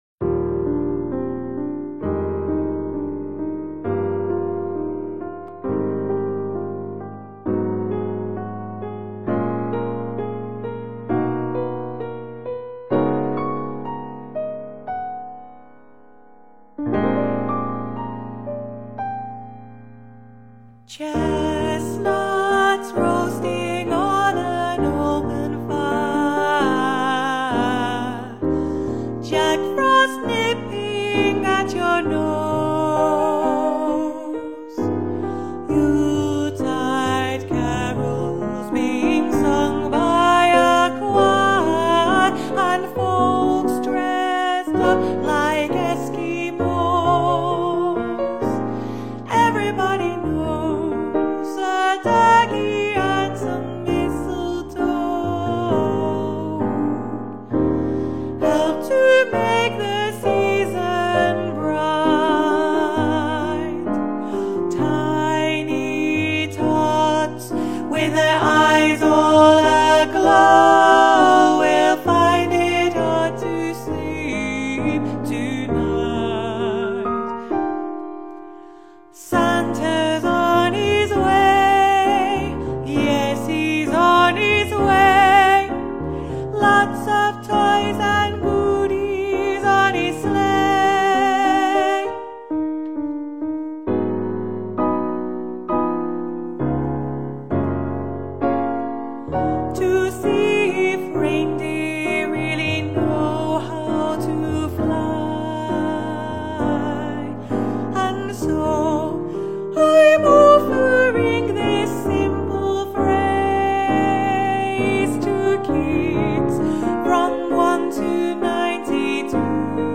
- Alto